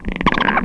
rocket_idle_chirp4.wav